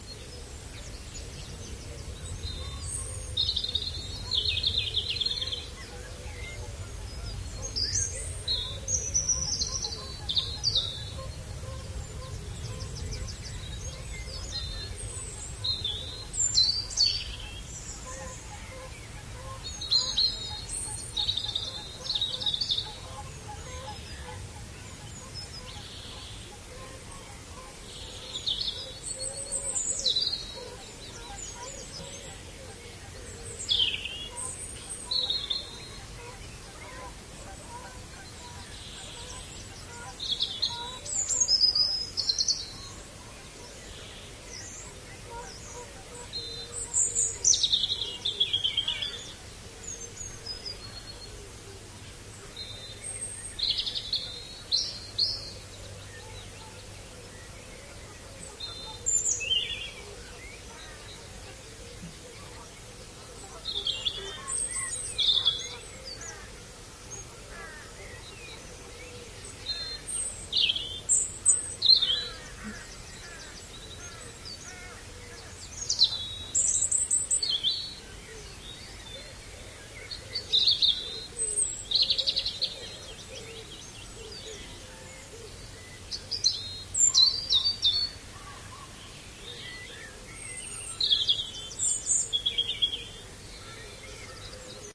这段录音已经有10多年的历史了，是在5月底的一个清晨完成的，使用的是固定在森海塞尔Dummyhead上的Soundman OKM麦克风和一台索尼TCDD7 DAT录音机。没有过滤器，有点嘈杂，但还是...
Tag: 双耳 dawnchorus 现场录音 森林上午 苏格兰